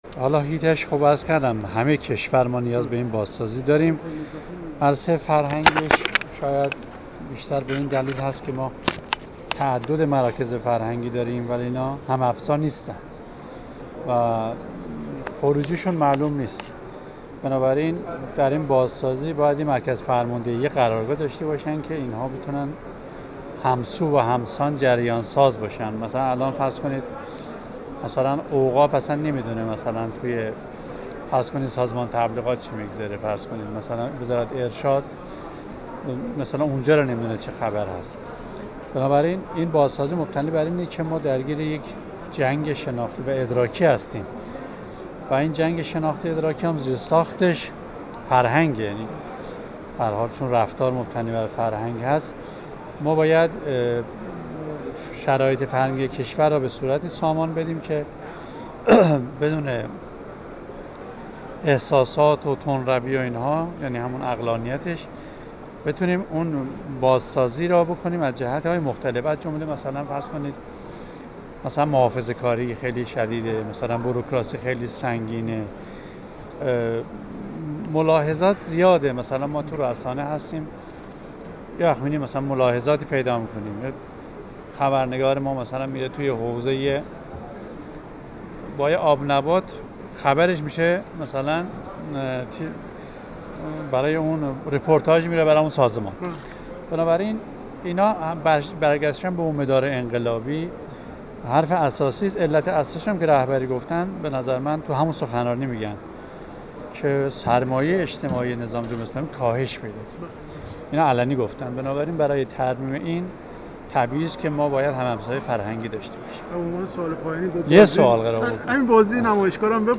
در گفت‌وگو با ایکنا در جریان بازدید از نمایشگاه دستاوردها و توانمندی‌های جهاددانشگاهی